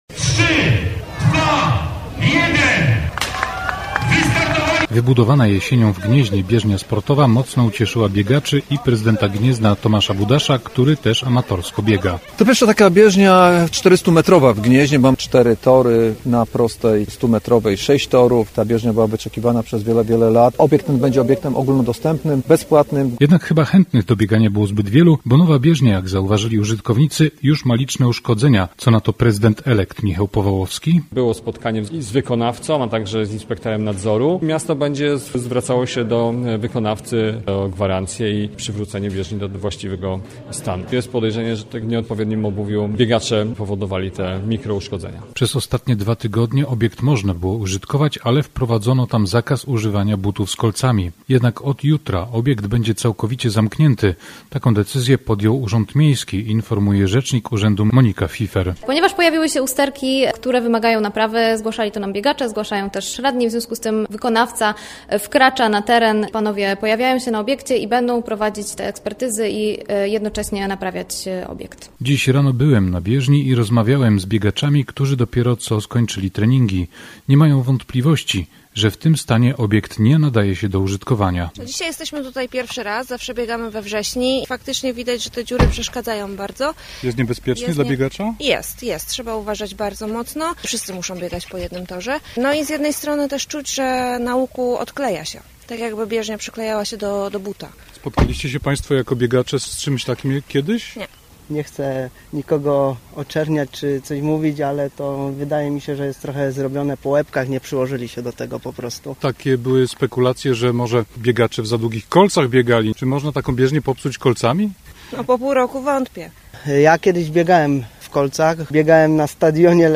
Jak mówią biegacze, z którymi rano rozmawiał nasz reporter, nawierzchnia nie jest bezpieczna.